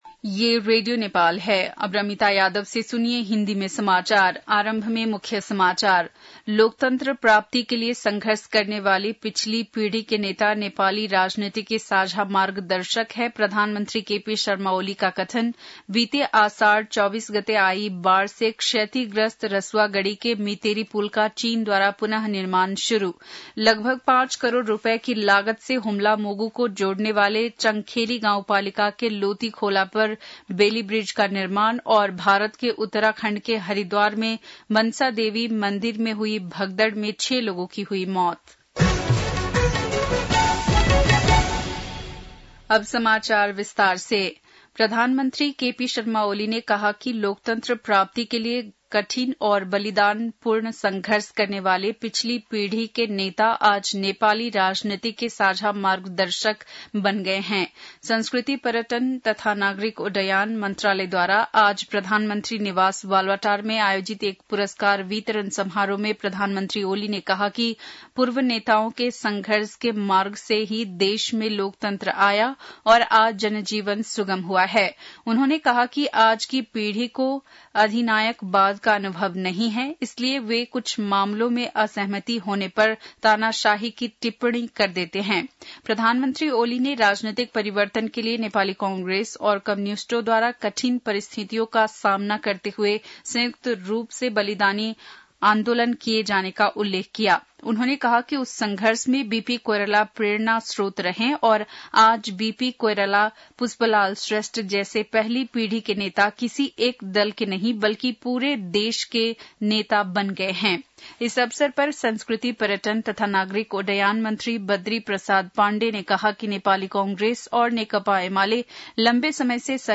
बेलुकी १० बजेको हिन्दी समाचार : ११ साउन , २०८२
10-pm-hindi-news-1-1.mp3